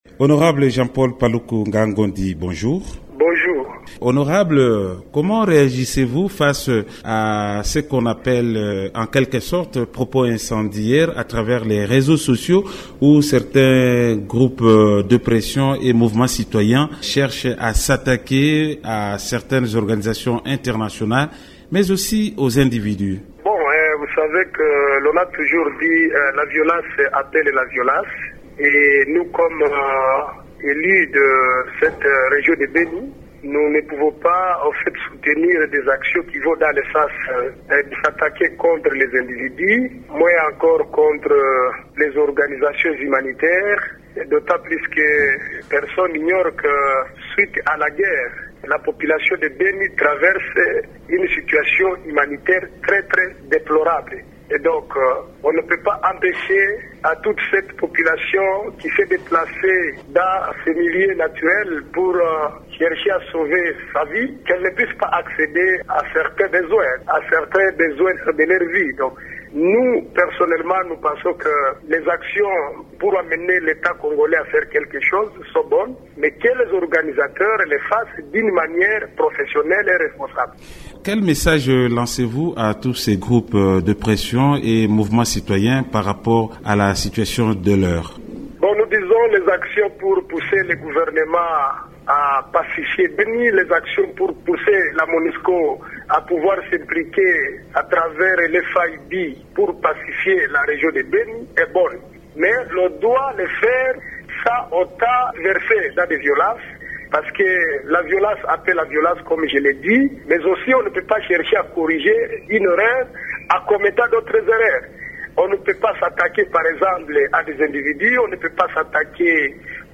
Le député provincial, élu du territoire de Beni, Jean-Paul Paluku Ngahangondi est l’invité de la rédaction aujourd’hui. Il commente les appels lancés ces derniers jours dans cette région contre des humanitaires et des organisations internationales.